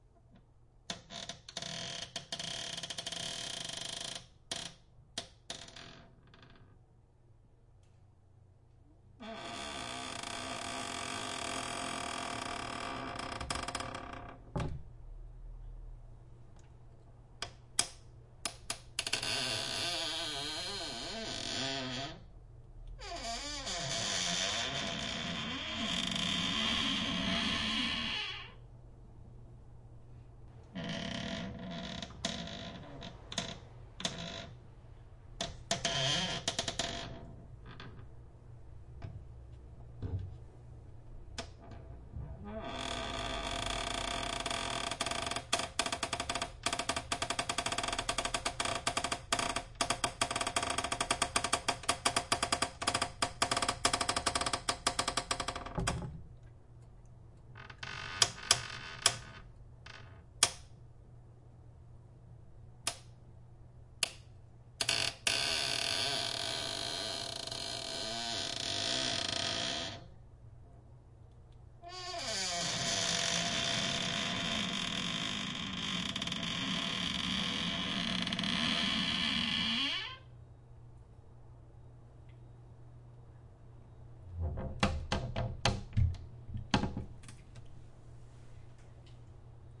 cupboard door squeaks
描述：Opening and closing cupboard doors that make squeaking sounds. Recorded with AT4021 mics into a modified Marantz PMD661.
标签： creak cupboard door eerie foley hinge open squeak
声道立体声